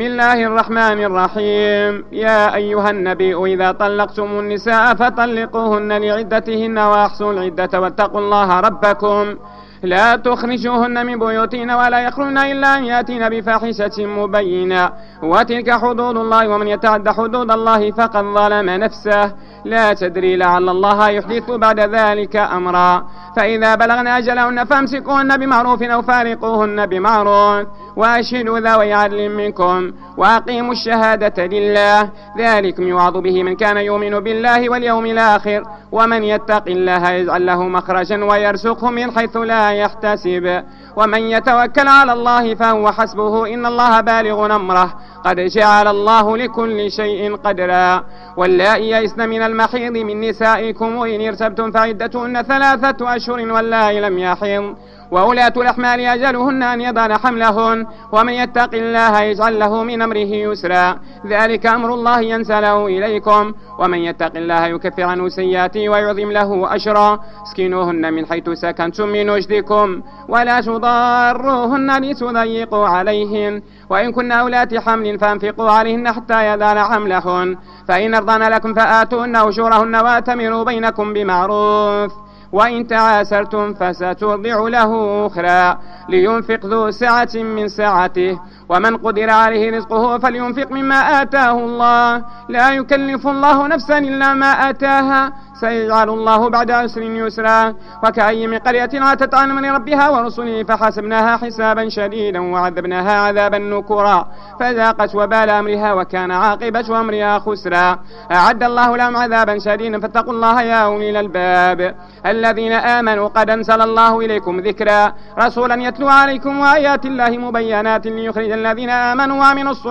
صلاة التراويح رقم 08 بمسجد ابي بكر الصديق فقارة الزوى